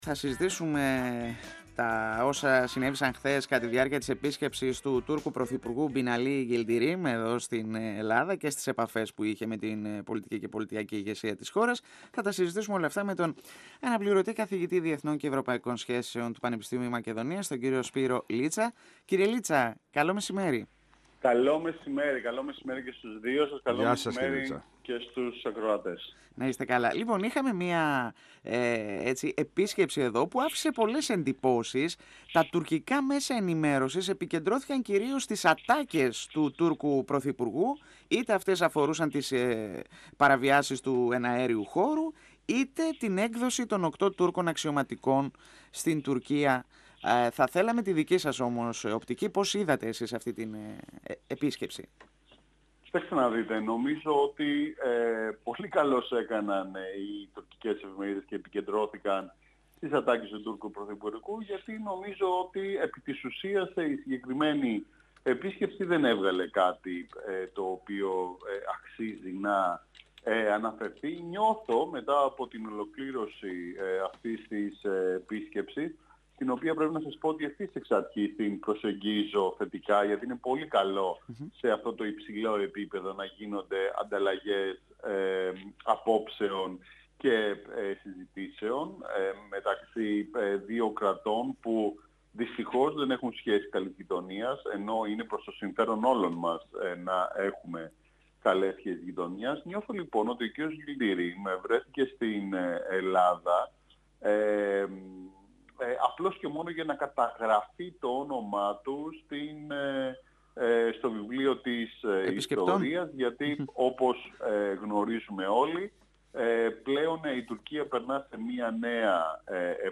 Μιλώντας στον 102 fm της ΕΡΤ3